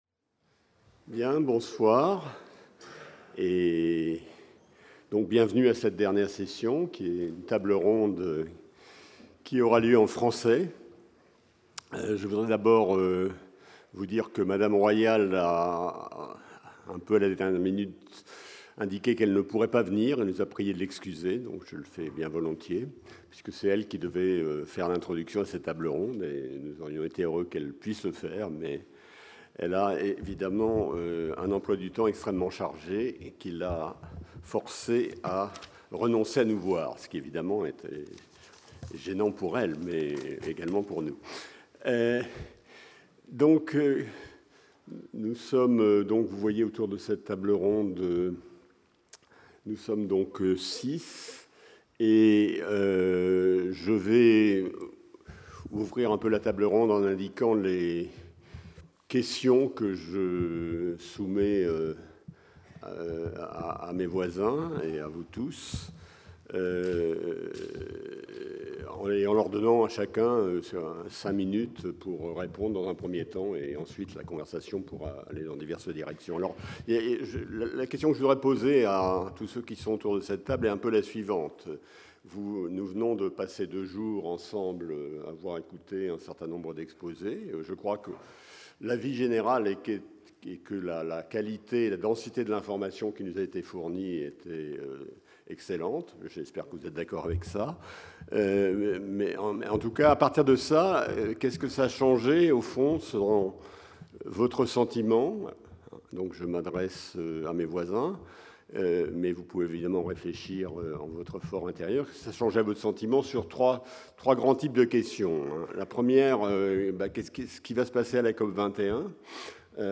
Table ronde | Collège de France